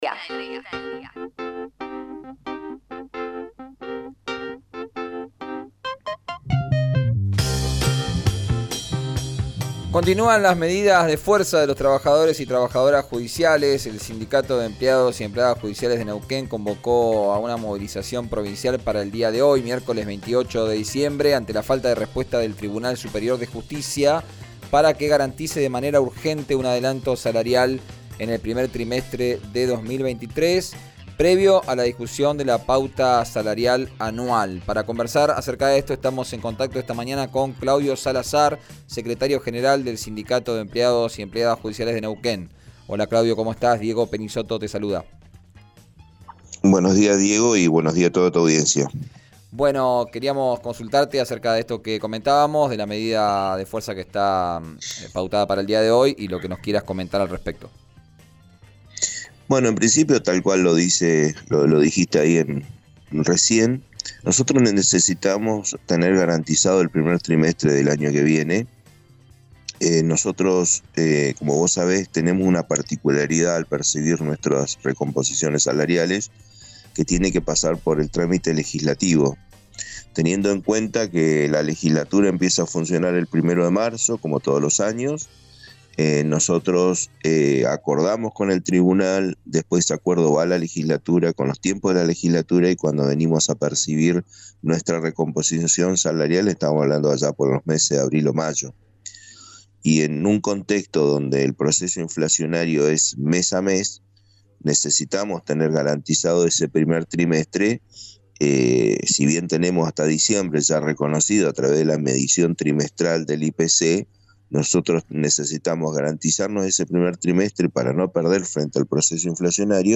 en “Arranquemos”, por RÍO NEGRO RADIO.